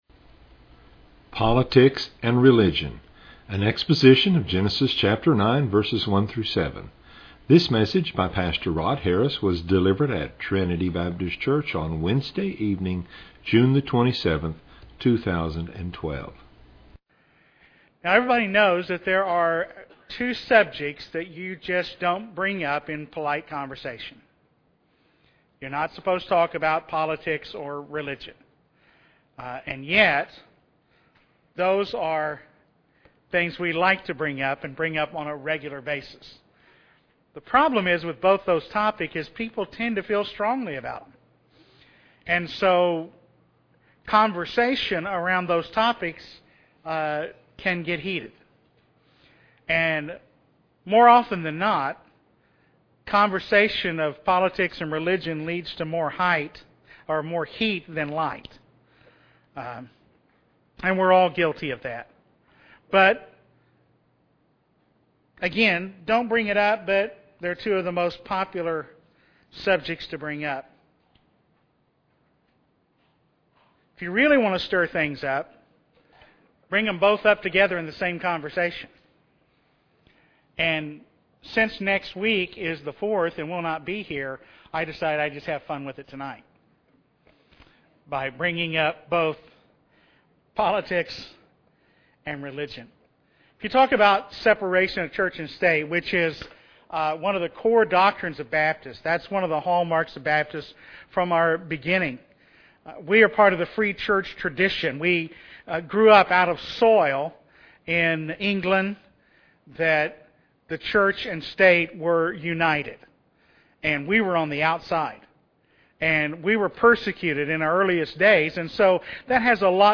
An exposition of Genesis 9:1-7.